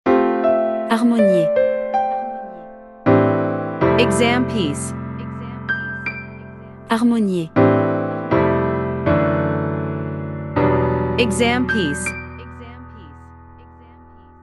Professional-level Piano Exam Practice Materials.
• Vocal metronome and beats counting
• Master performance examples